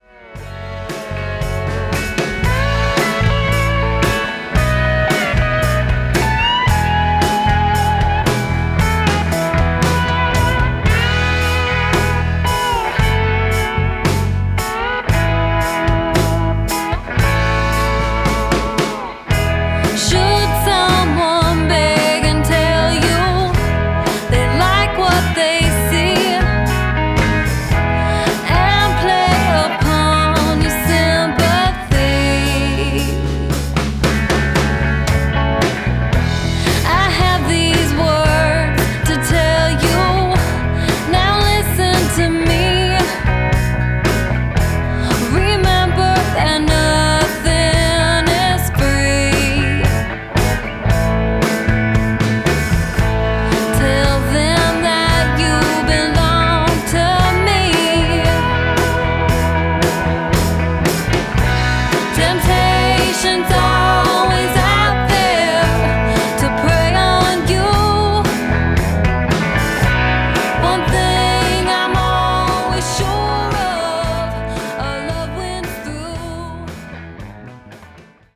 Recorded at Third Ward Records February 2015